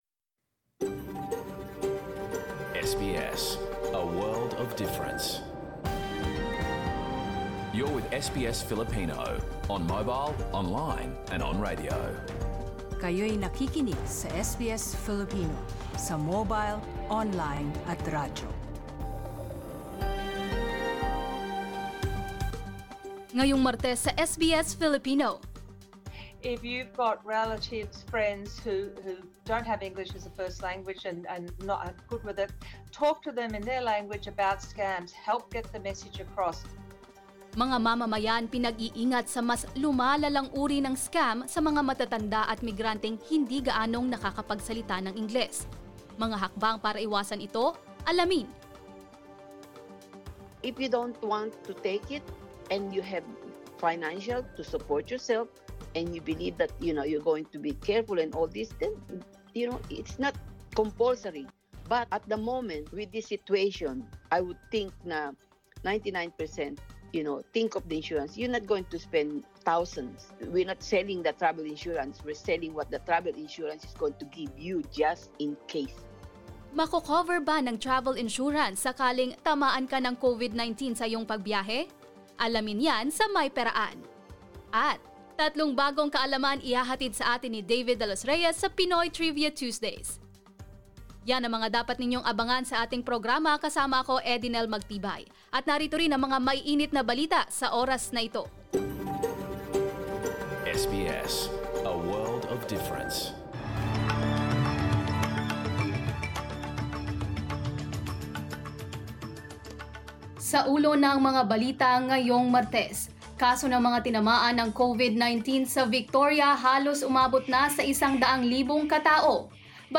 filipino_news_november_9.mp3